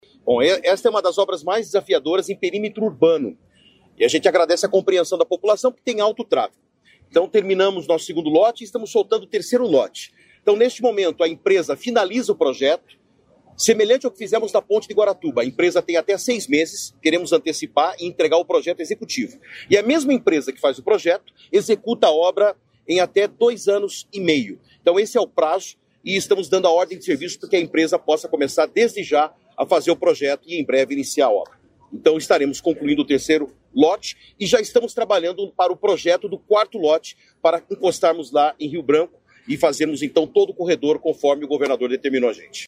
Sonora do secretário Estadual das Infraestrutura e Logística, Sandro Alex, sobre a entrega da duplicação do Lote 2 da Rodovia dos Minérios